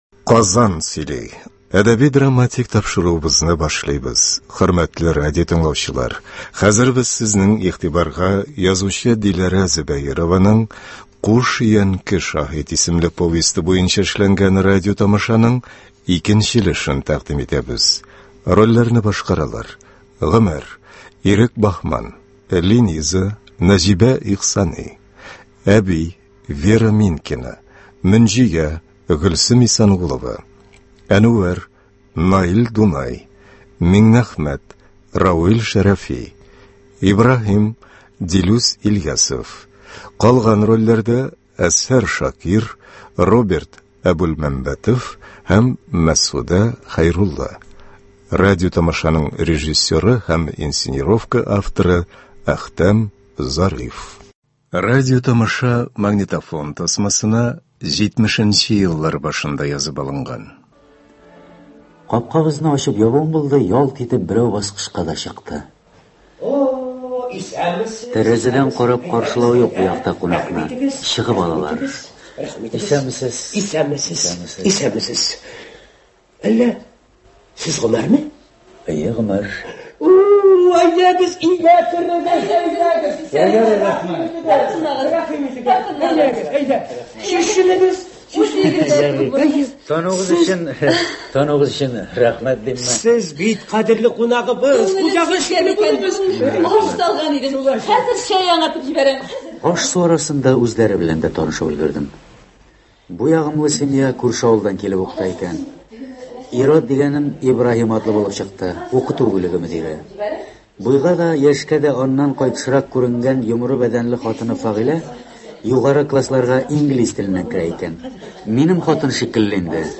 “Куш өянке шаһит”. Радиотамаша.